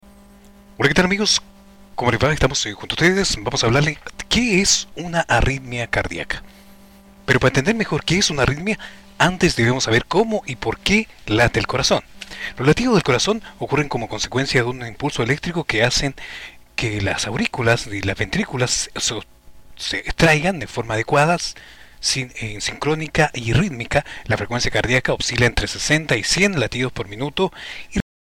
Masculino
Espanhol - Chile